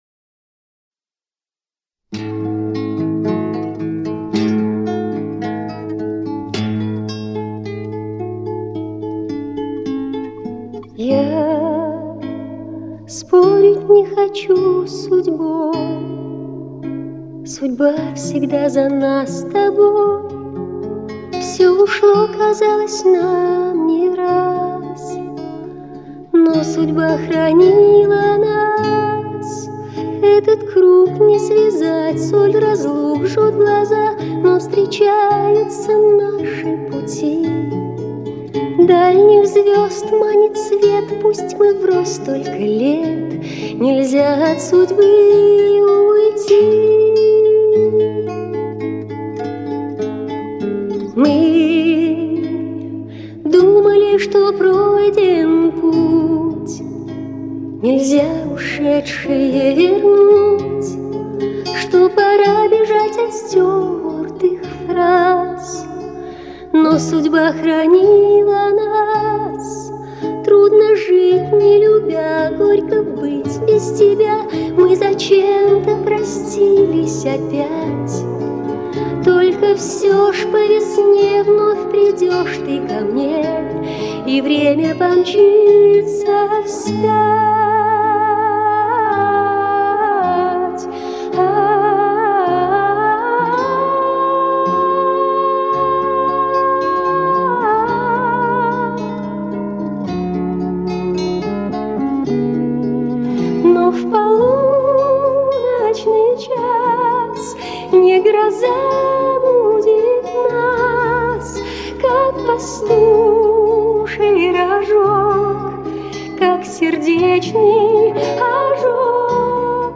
Лирические песни 70-80-х годов